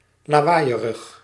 Ääntäminen
IPA: /laʊ̯t/